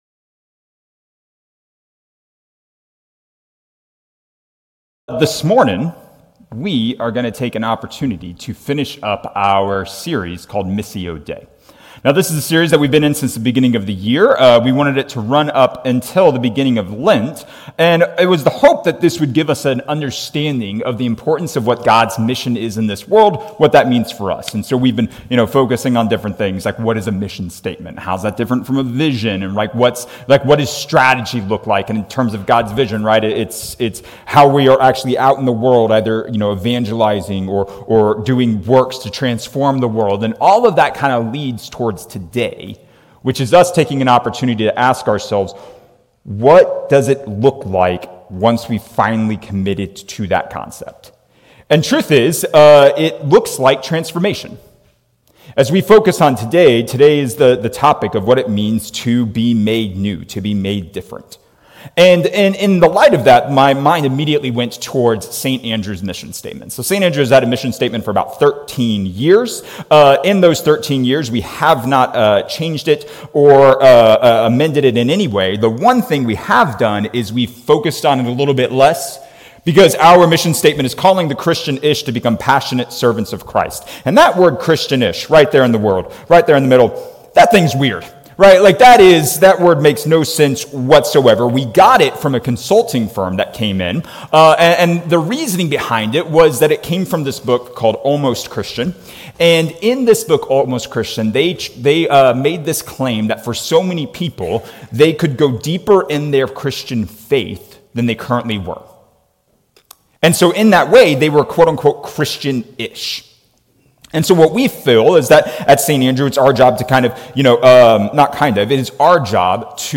A message from the series "Missio Dei."